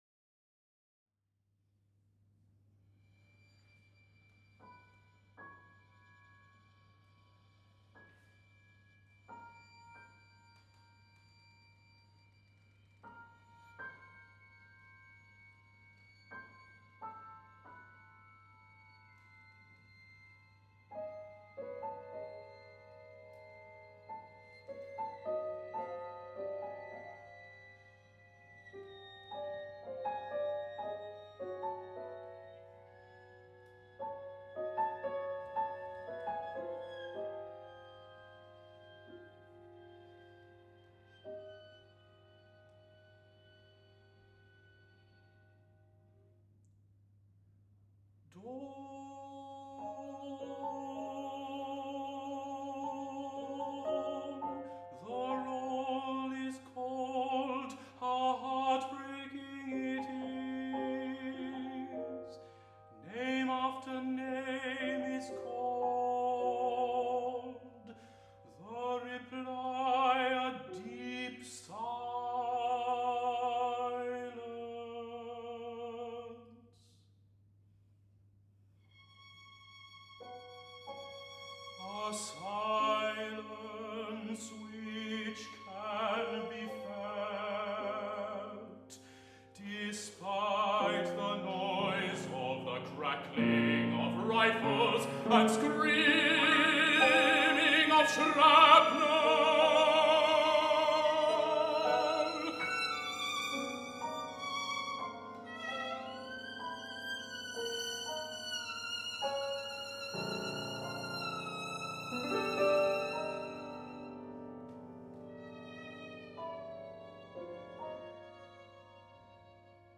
piano
violin
Live: RCM Masters Recital, June 2016